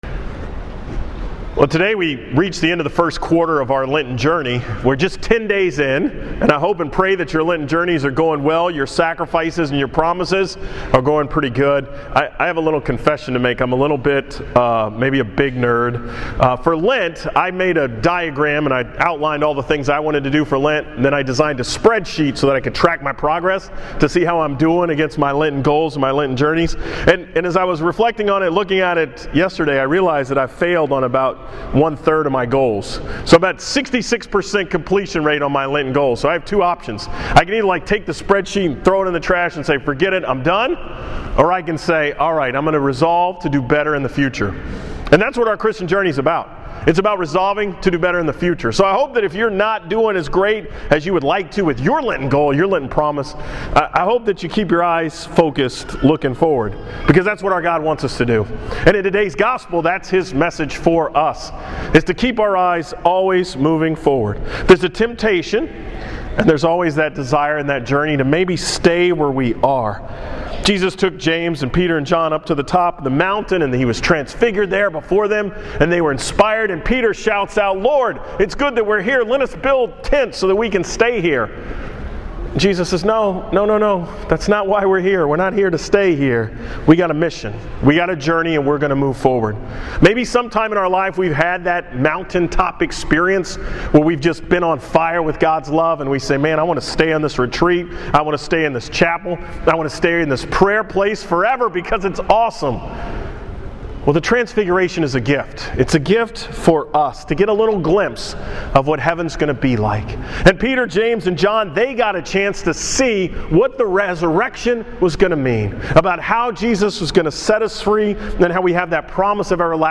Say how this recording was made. From the 11 am Mass at St. James in Spring on March 1, 2015